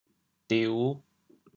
调: 池 国际音标 [tiu]
diu5.mp3